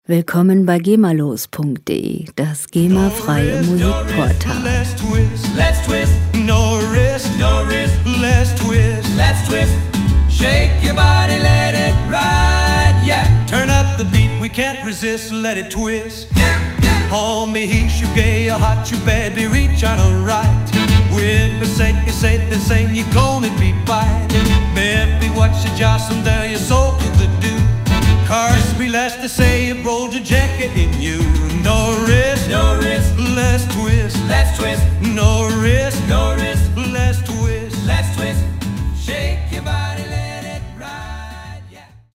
Musikstil: Rock 'n' Roll
Tempo: 166 bpm
Tonart: A-Dur
Charakter: mitreißend, wuchtig